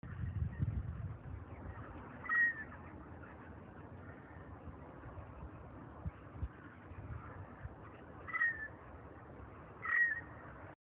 field recording